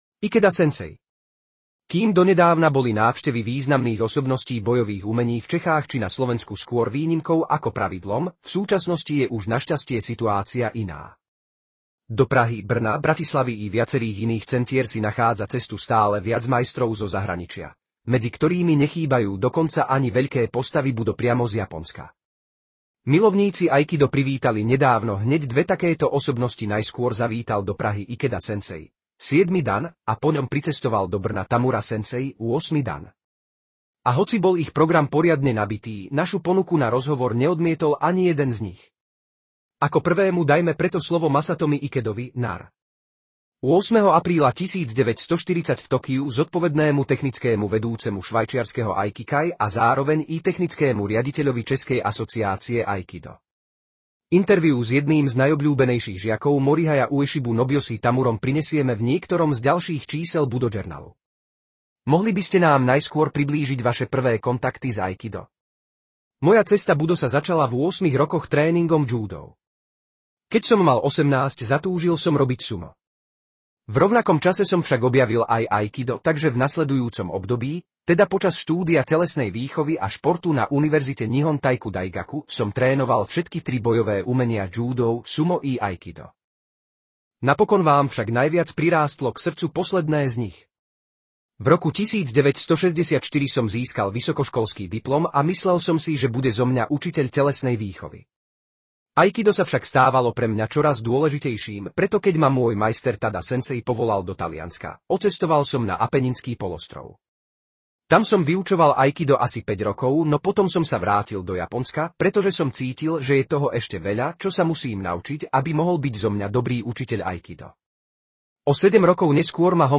Rozhovor: Budo Journal 1994